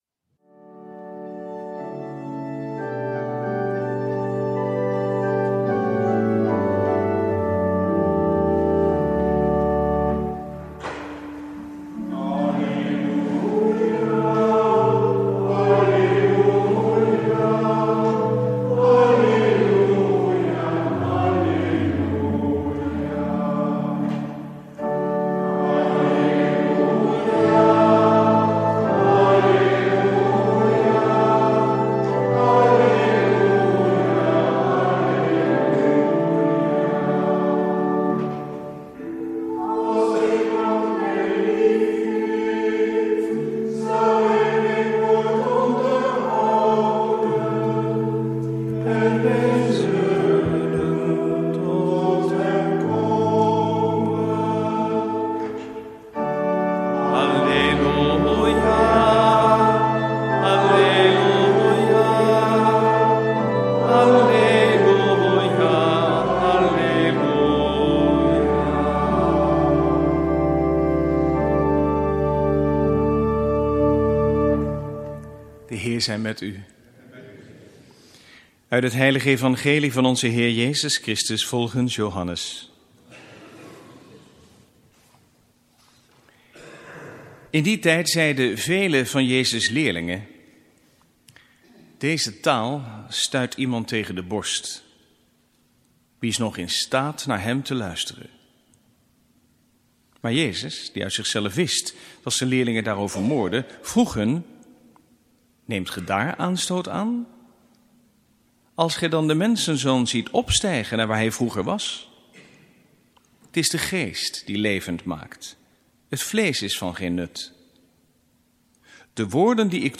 Preek 21e zondag, door het jaar B, 22/23 augustus 2015 | Hagenpreken